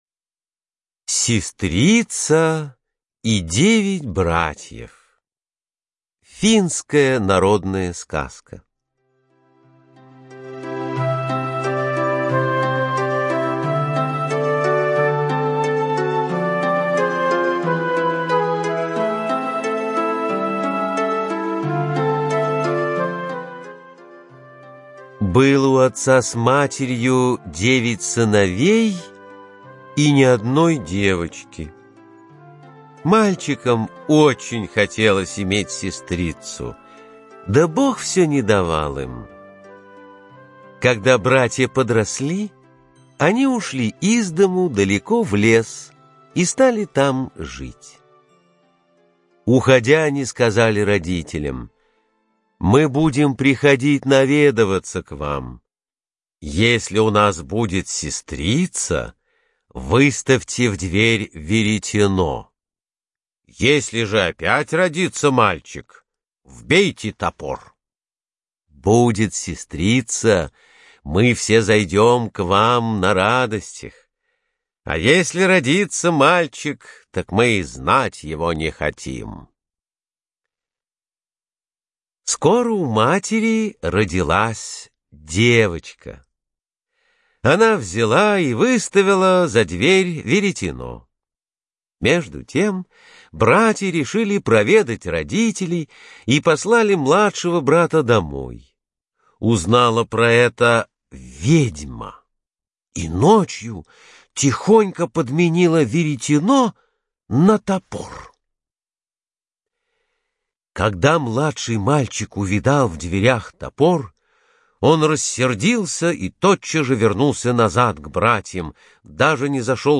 Сестрица и девять братьев - финская аудиосказка - слушать онлайн